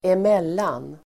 Uttal: [²em'el:an]